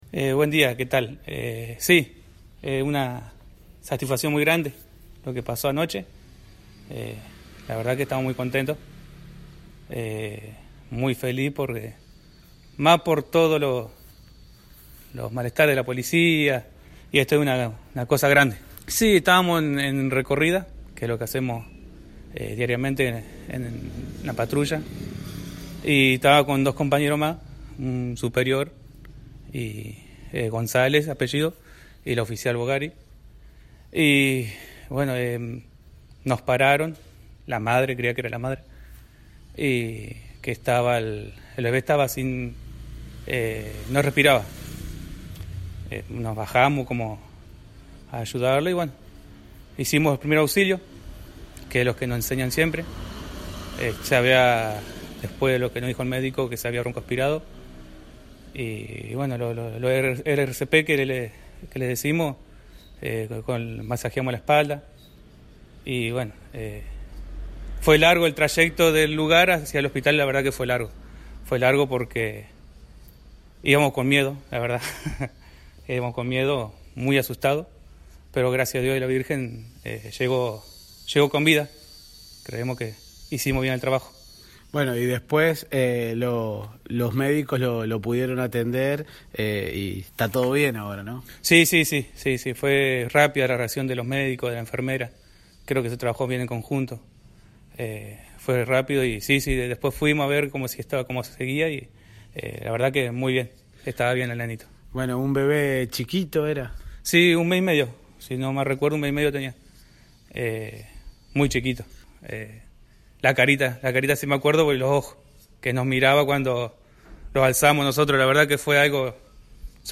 Escuchá el emotivo relato